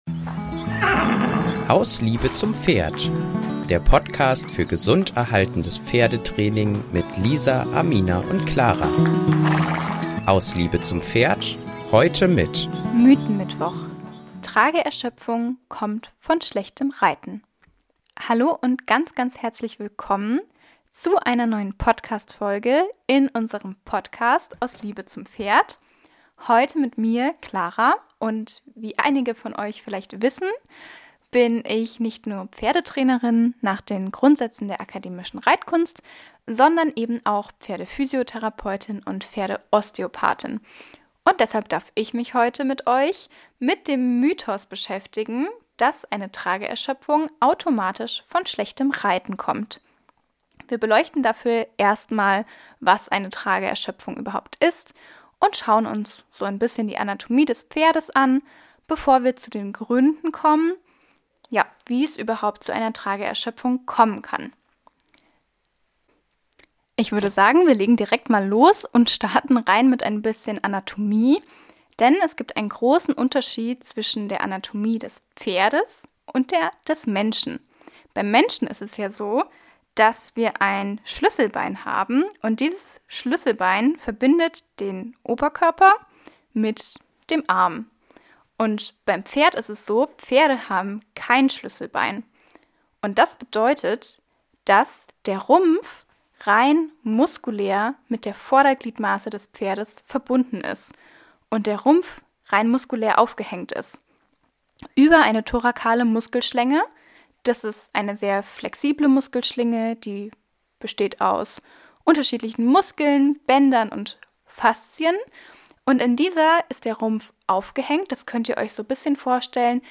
Weil bekanntlich alle guten Dinge drei sind, treffen in diesem Podcast gleich drei Pferdefrauen aufeinander.